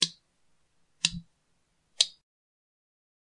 描述：Sonido de chasqueo con los dedos